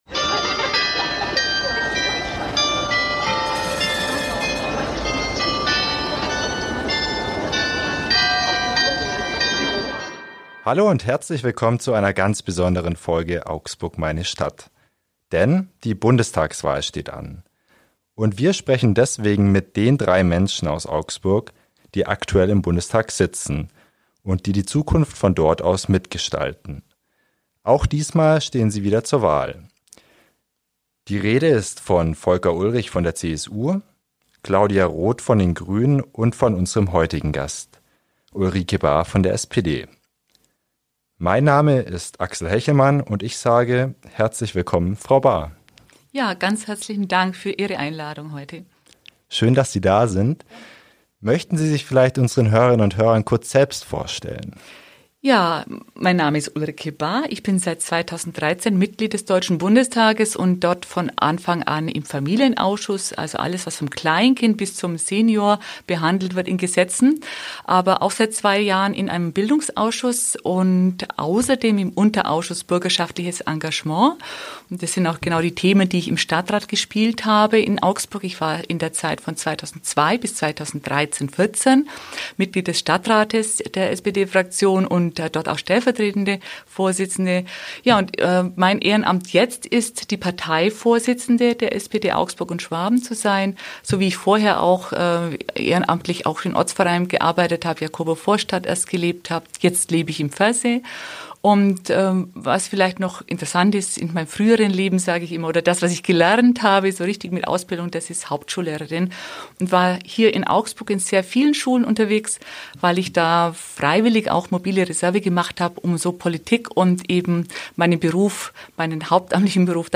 In dieser Folge unseres Podcasts "Augsburg, meine Stadt" spricht die SPD-Politikerin Ulrike Bahr über politische Ziele, Probleme in Augsburg und sein Privatleben.